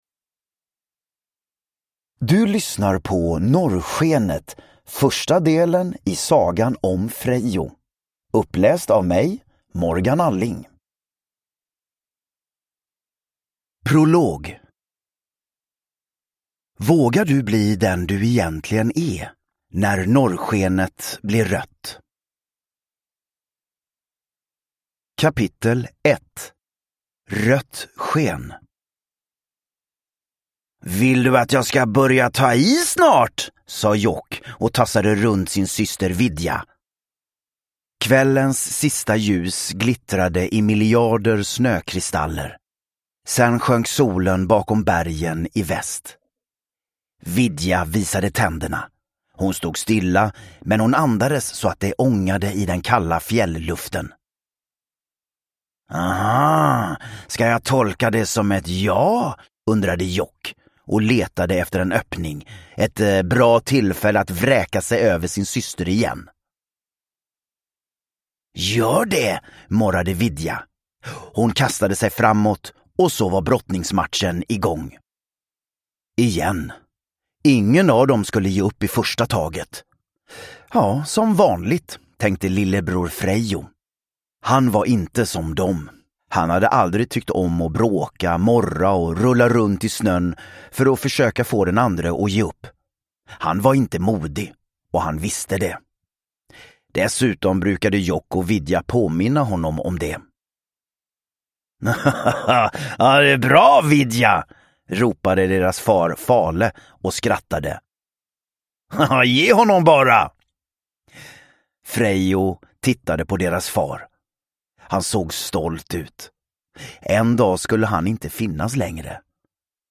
Norrskenet (ljudbok) av Fredrik Blomqvist